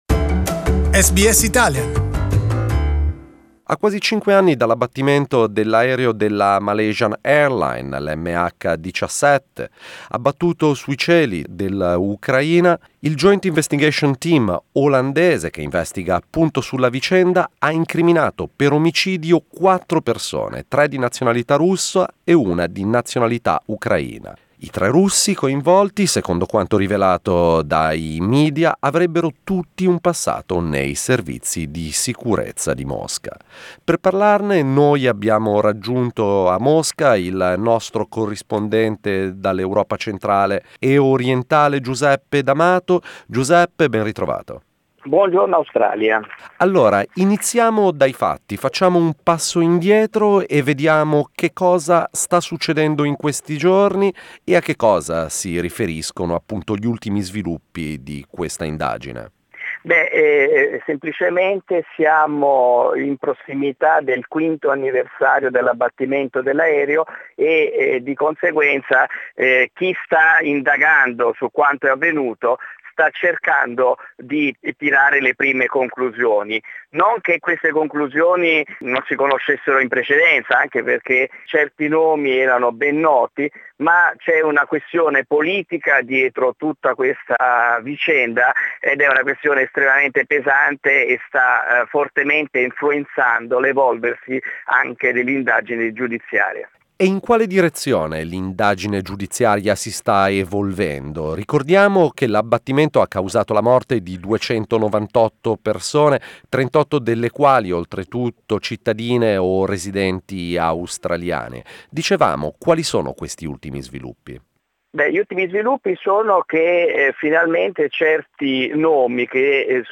Moscow based journalist